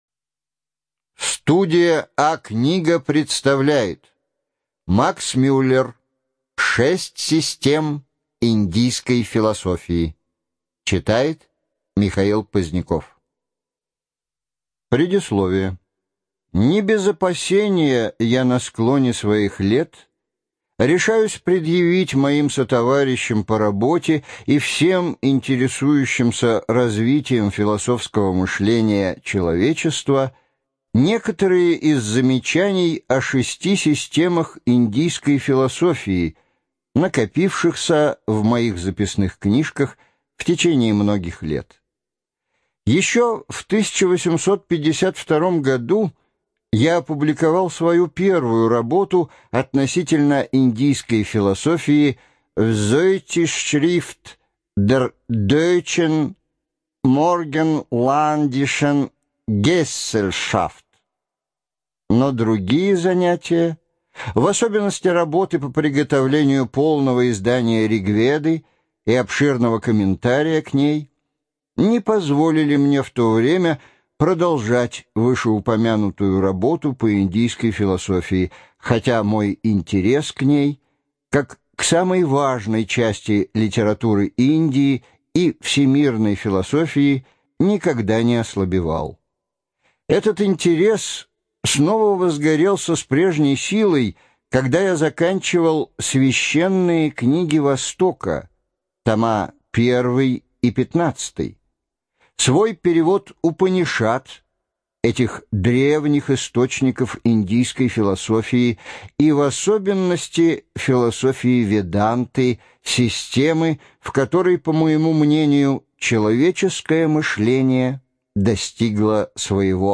Студия звукозаписиМедиакнига